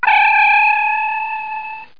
00023_Sound_Energize.mp3